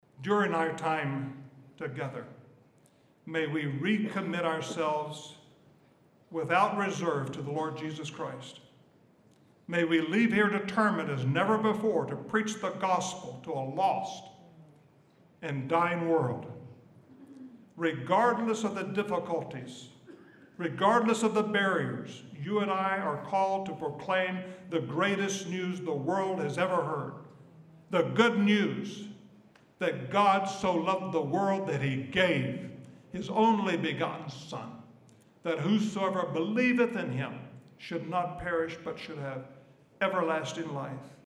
Franklin Graham opened the World Summit in Defense of Persecuted Christians in Washington, DC.